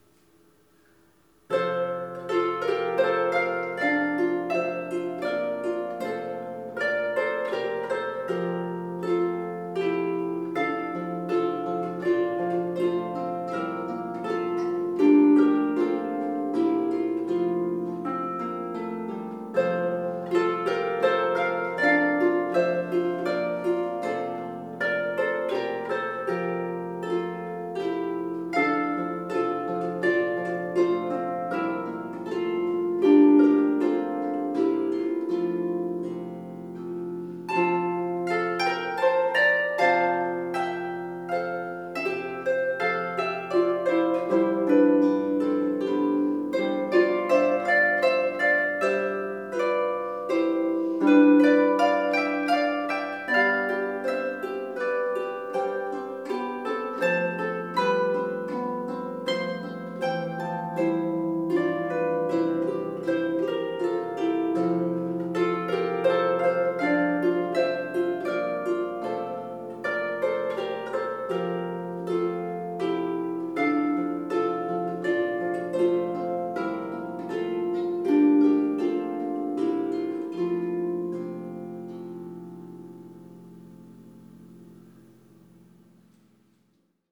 for two lever or pedal harps